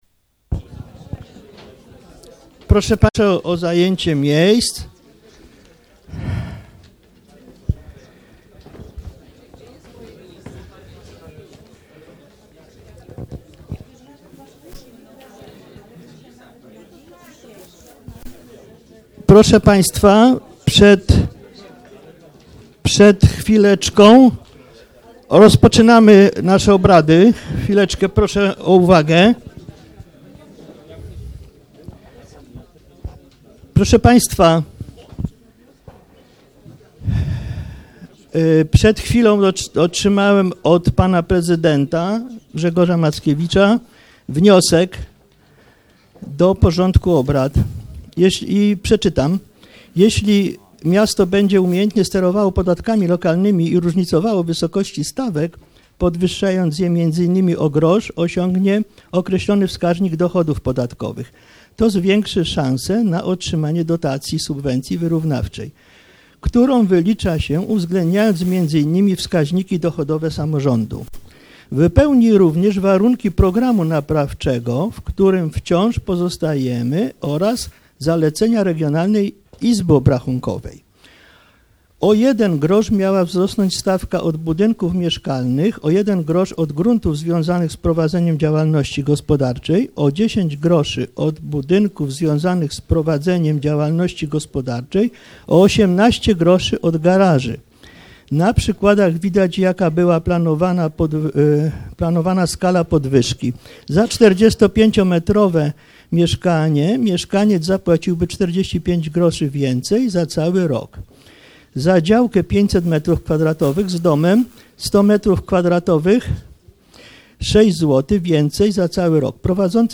XVIII sesja Rady Miejskiej w Pabianicach - 5 listopada 2015 r. - 2015 rok - Biuletyn Informacji Publicznej Urzędu Miejskiego w Pabianicach